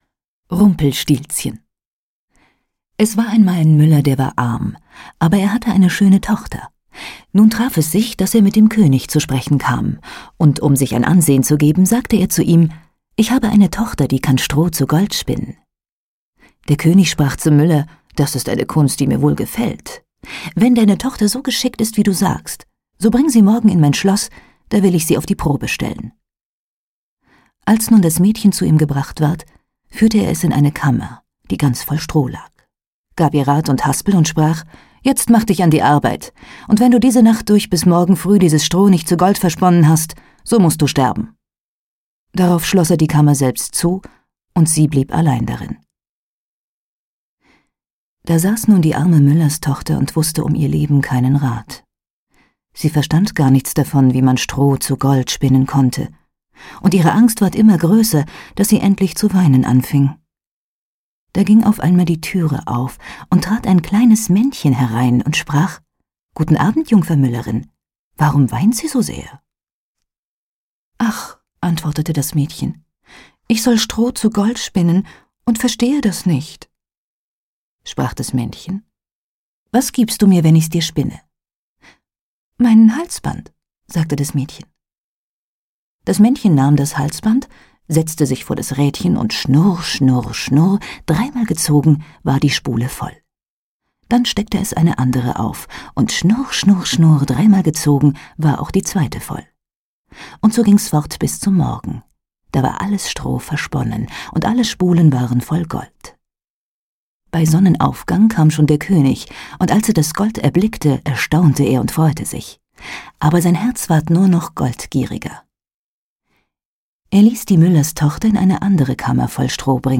Schlagworte Grimm • Hörbuch • Kinder- und Hausmärchen • Märchen